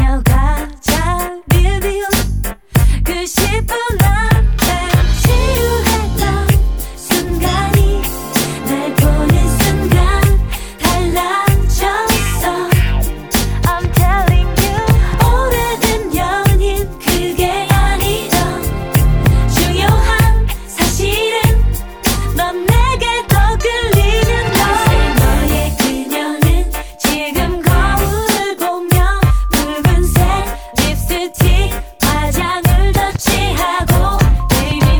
Жанр: K-pop / Поп